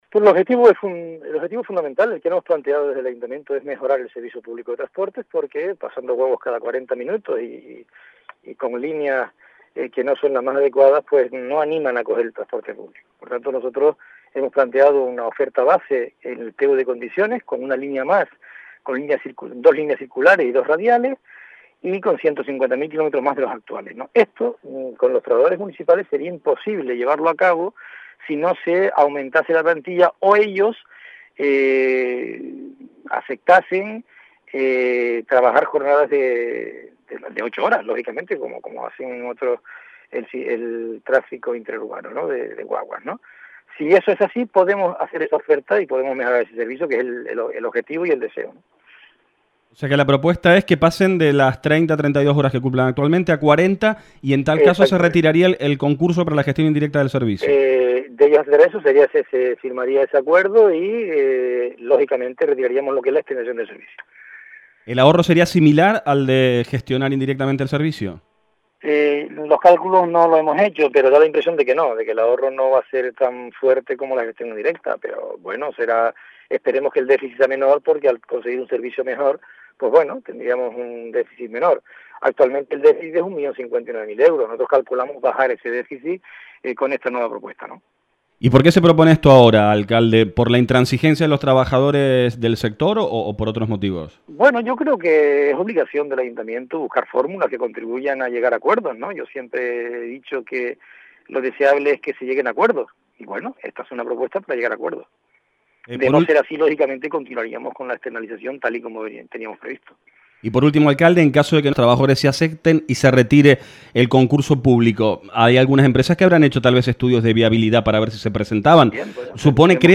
/ Escuche al alcalde